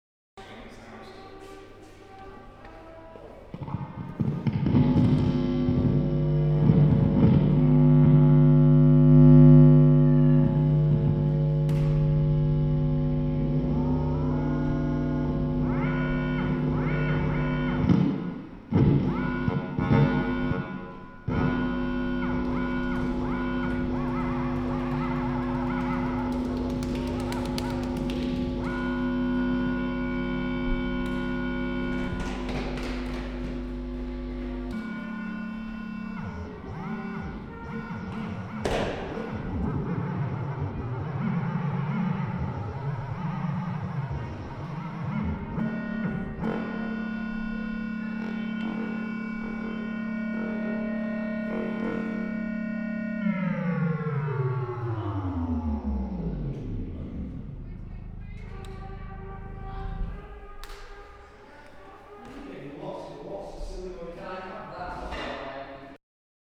INSTRUCTIONS: Create an audio file which sounds like you, without speaking.
Random noises, experimental sounds and esoteric BANG-WALLOP-BING-BAZOOODLES [Download]
withouttalking-88459.mp3